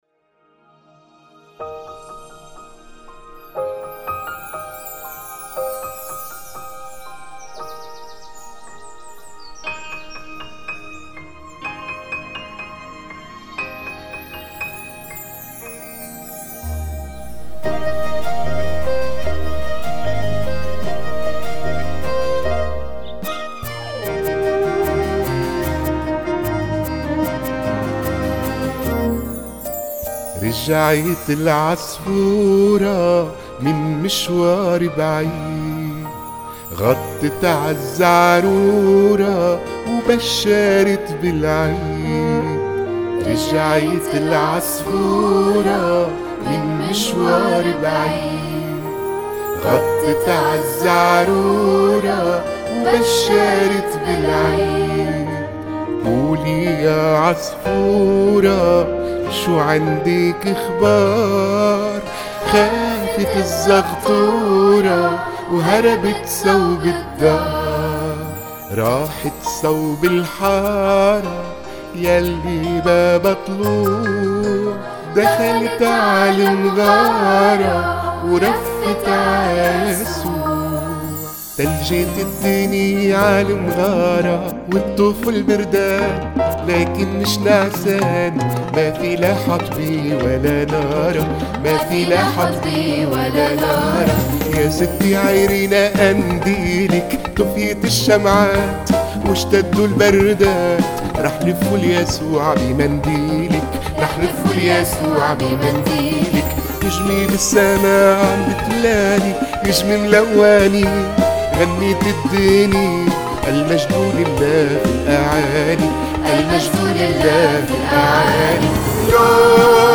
غنيّة ميلادية
اصوات الأطفال :